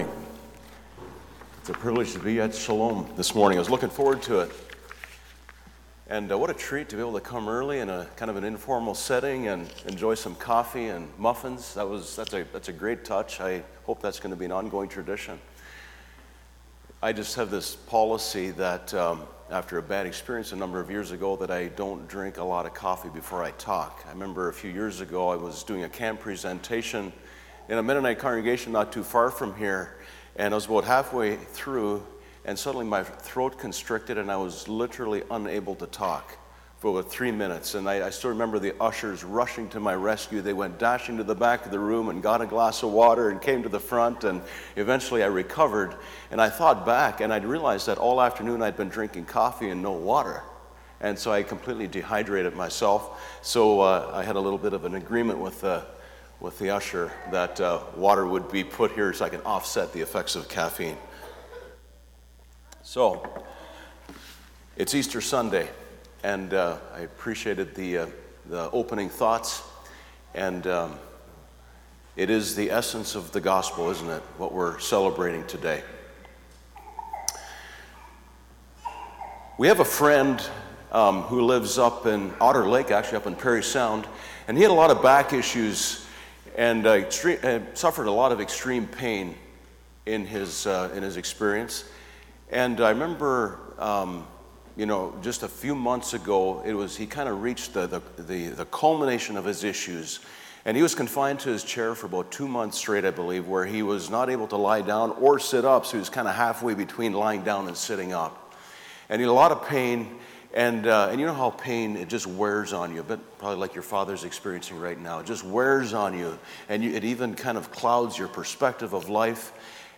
Series: Guest Speakers, Sunday Sermons